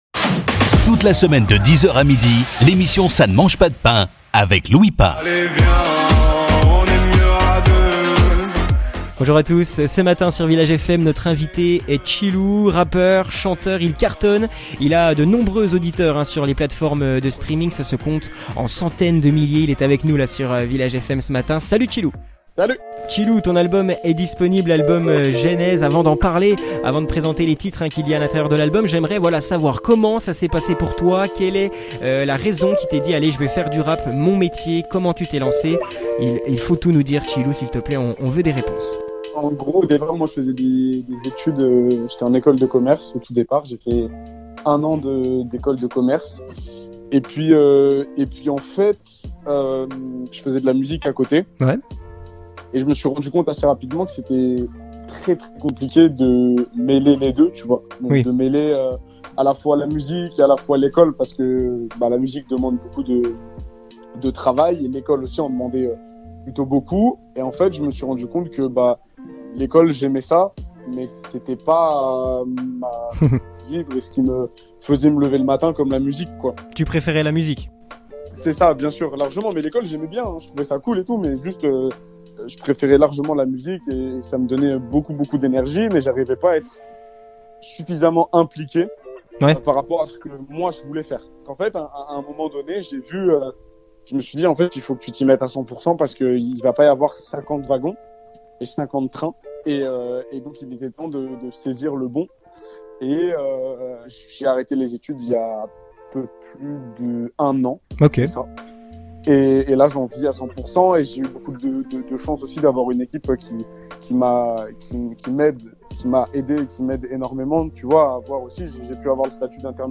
Retrouvez les interviews des artistes de la 21ème édition...
Festival de la Paille 2023, retrouvez les interviews des artistes